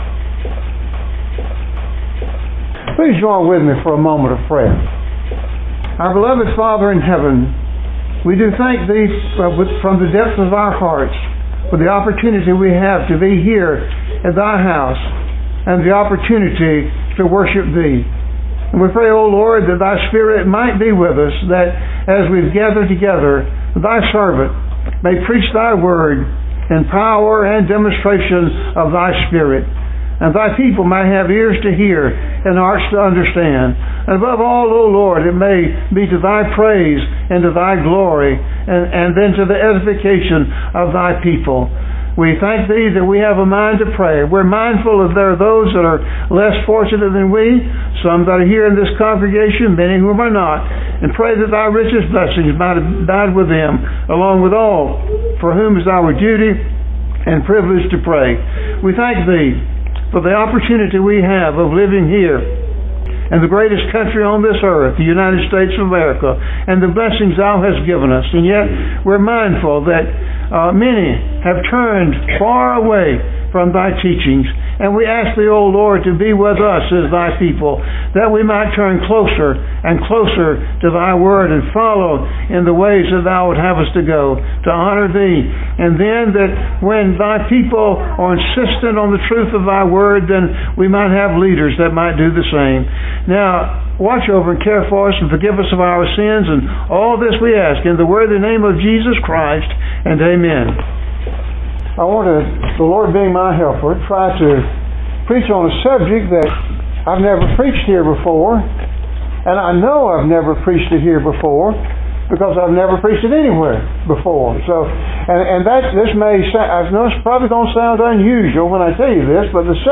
Exodus 26:1, Linen, A Type Of Jesus Mar 7 In: Sermon by Speaker